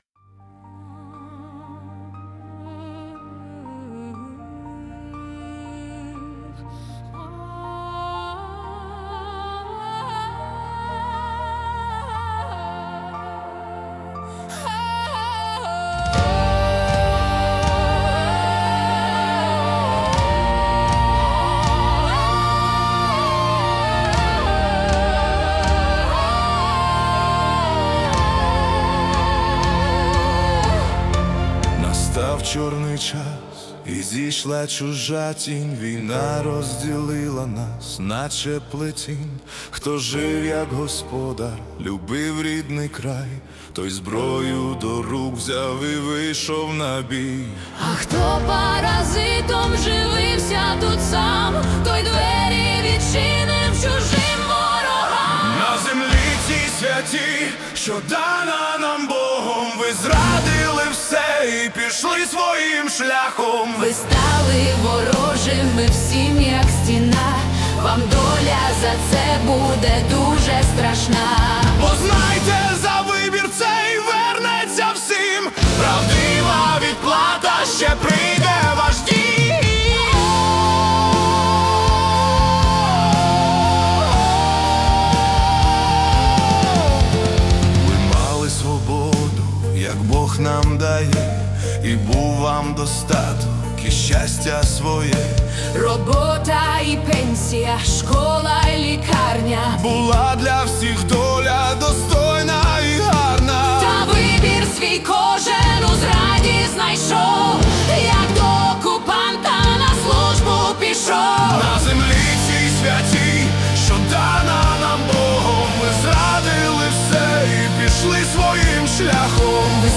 Dramatic Rock / Orchestral Ballad
Музика підкреслює гнів землі і невідворотність Божого суду.